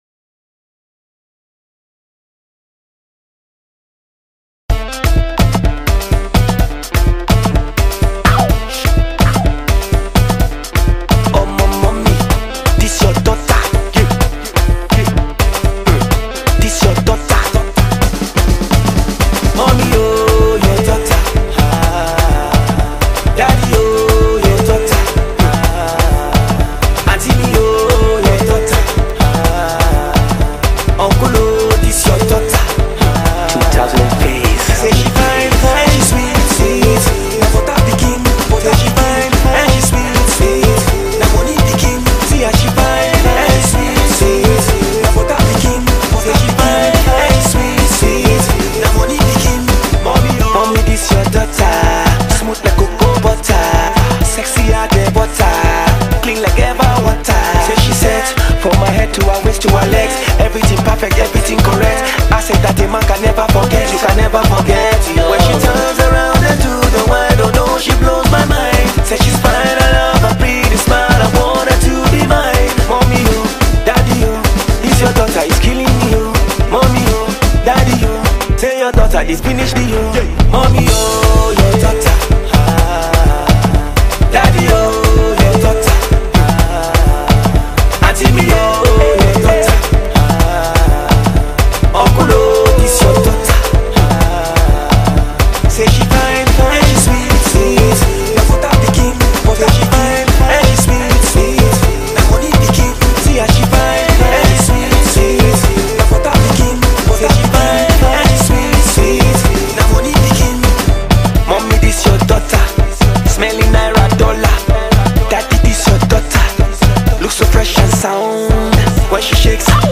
dance record